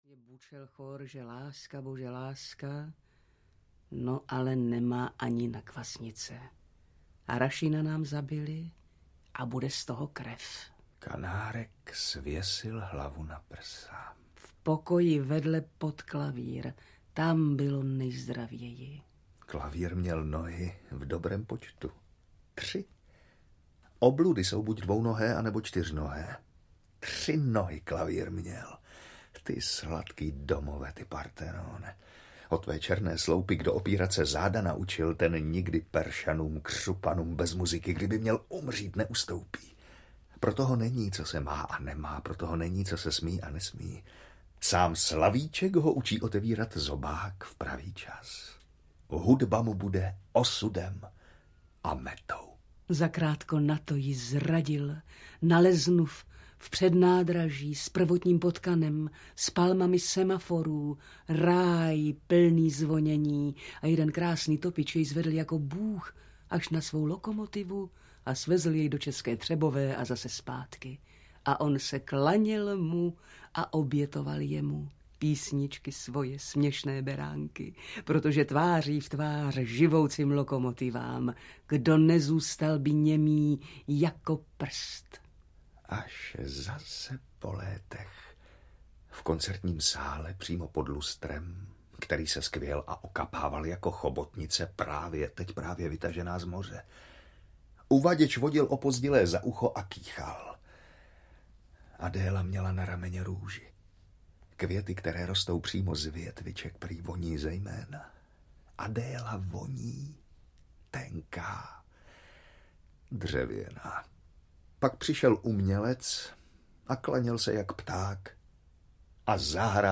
Jakoubku, cos měl s tím andělem? - audiokniha obsahuje nahrávky přednesu básní, které napsal Josef Kainar (Kainar je v tomto případě i autorem hudebního doprovodu).